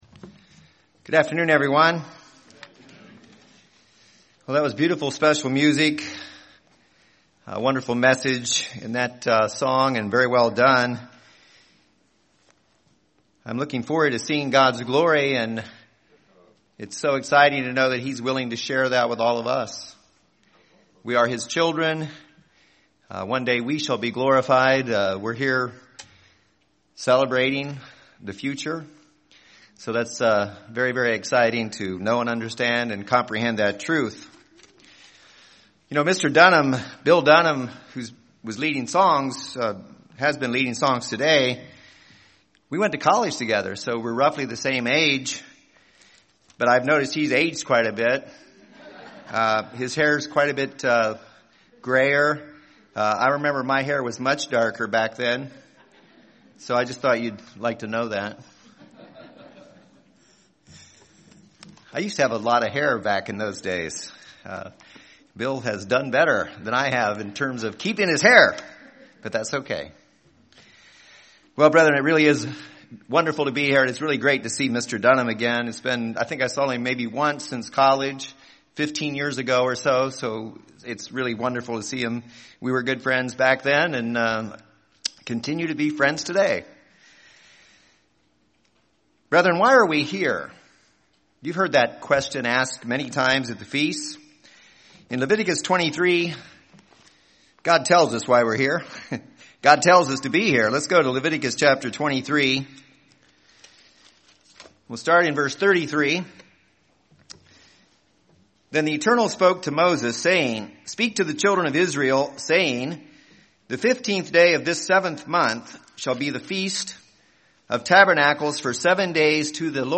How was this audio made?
This sermon was given at the Cincinnati, Ohio 2020 Feast site.